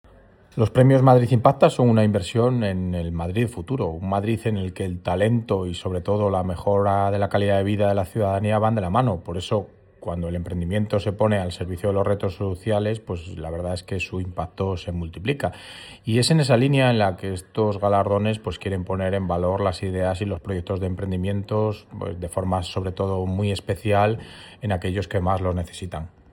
Nueva ventana:El concejal delegado de Innovación y Emprendimiento, Ángel Niño: